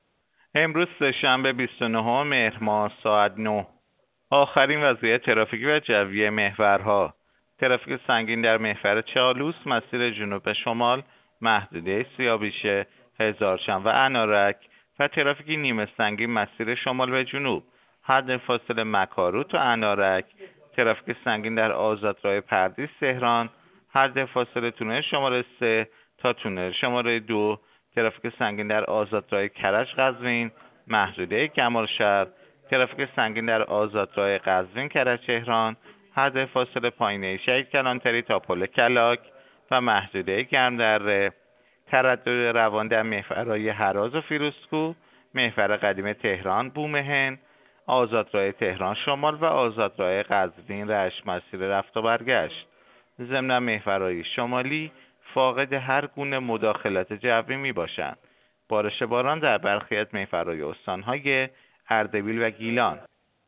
گزارش رادیو اینترنتی از آخرین وضعیت ترافیکی جاده‌ها ساعت ۹ بیست‌ونهم مهر؛